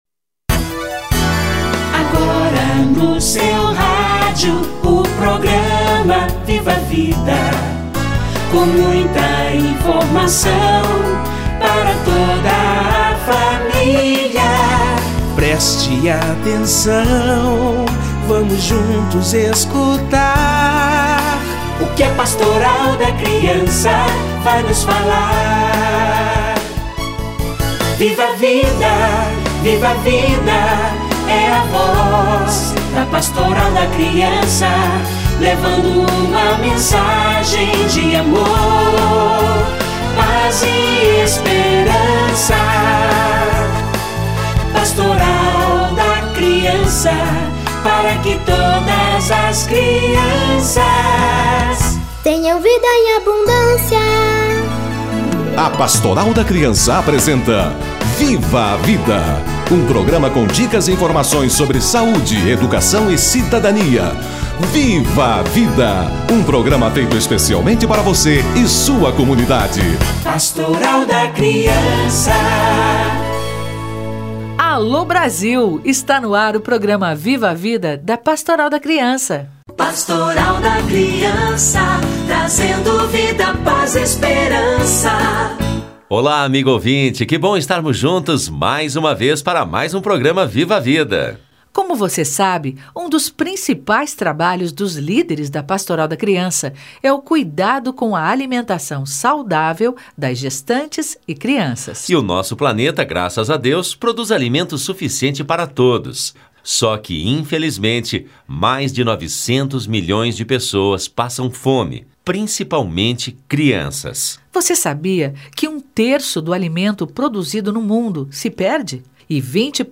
Como evitar o desperdício de alimentos - Entrevista